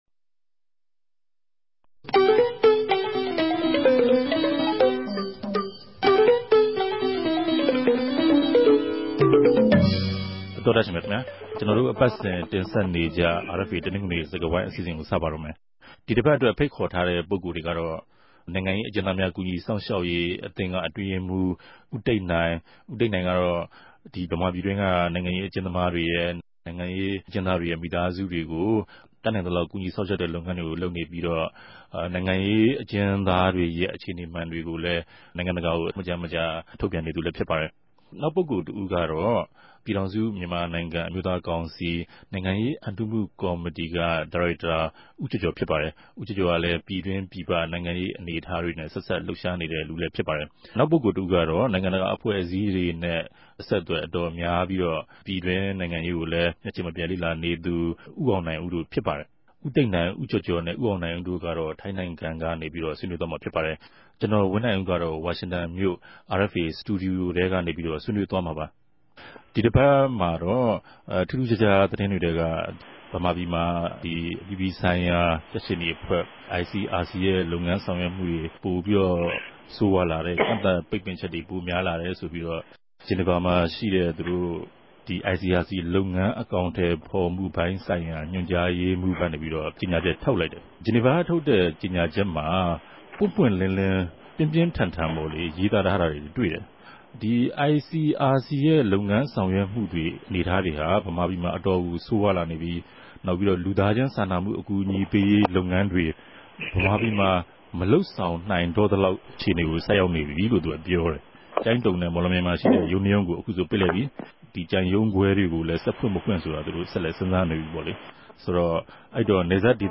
တယ်လီဖုန်းနဲႛ ဆက်သြယ်္ဘပီး၊ ပၝဝင်ထားုကပၝတယ်၊၊
တနဂဿေိံြ ဆြေးေိံြးပြဲစကားဝိုင်း